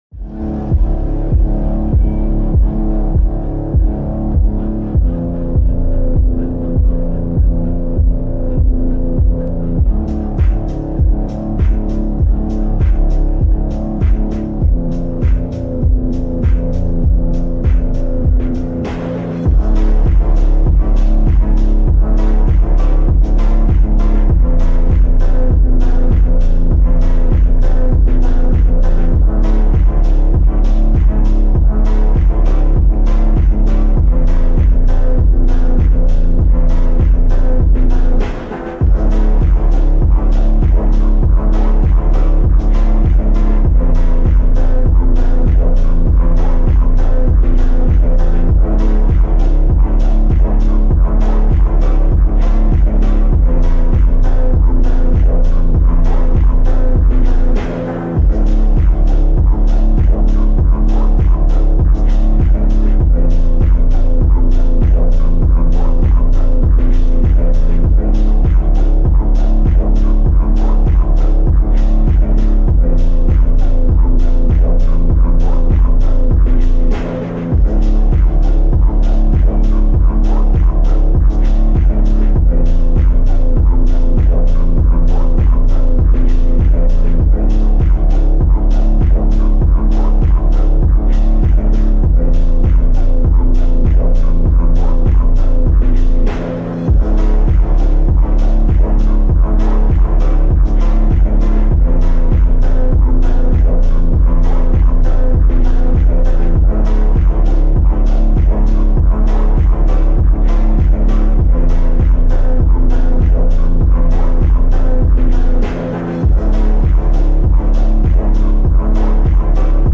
Slowed + Reverb + Eco + Bass Boosted × Epicenter Bass